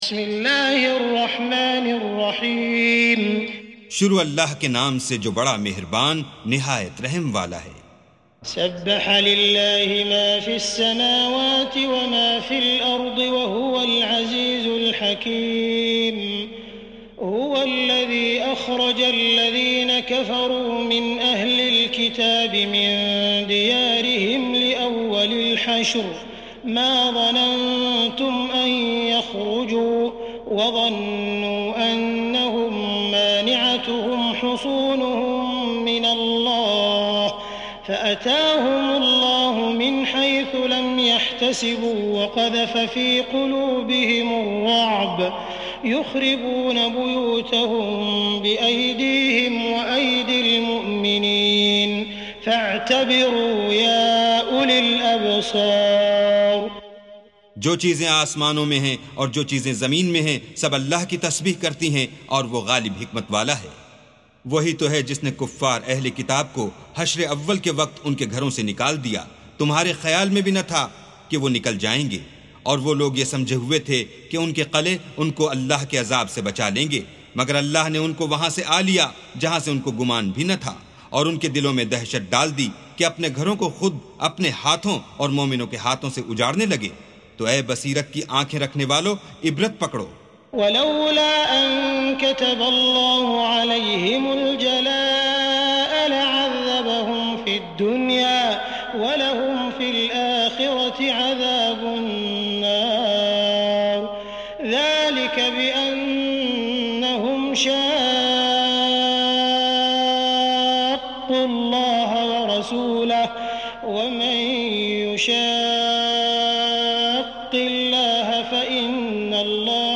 سُورَةُ الحَشۡرِ بصوت الشيخ السديس والشريم مترجم إلى الاردو